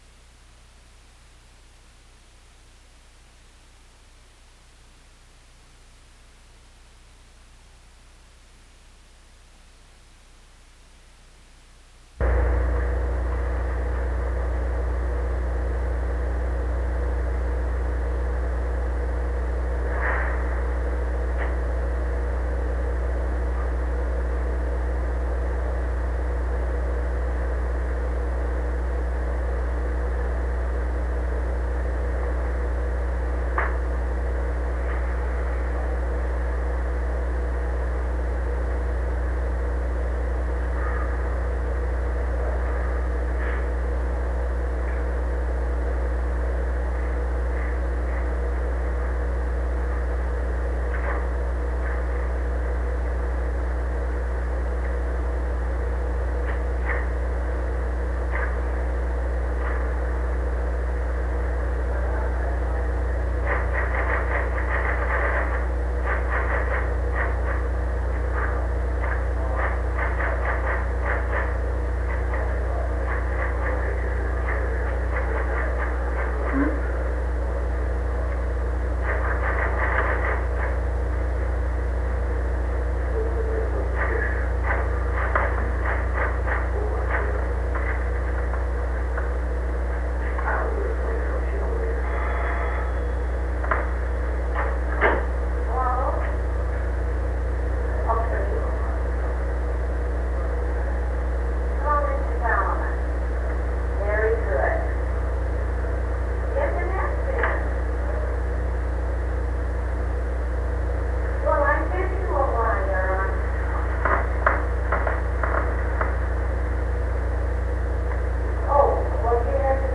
State of the Union Rehearsal
Secret White House Tapes | John F. Kennedy Presidency State of the Union Rehearsal Rewind 10 seconds Play/Pause Fast-forward 10 seconds 0:00 Download audio Previous Meetings: Tape 121/A57.